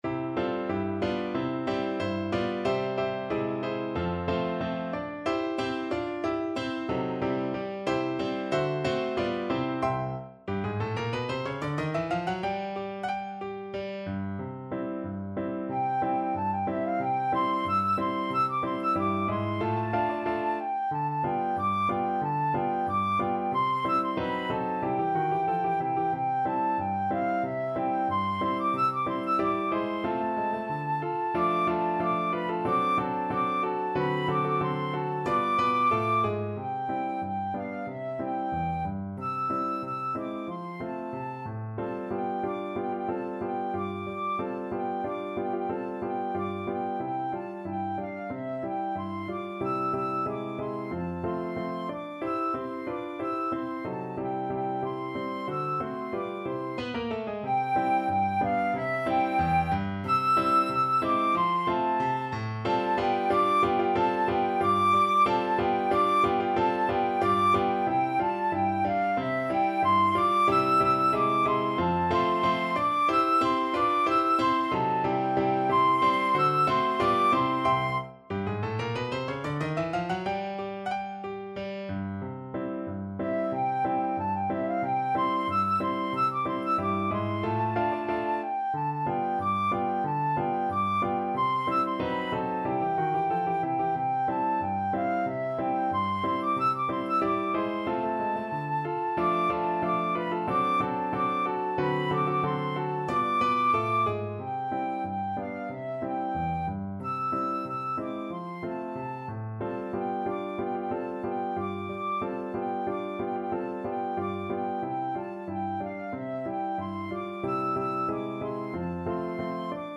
Allegretto =92
Traditional (View more Traditional Flute Music)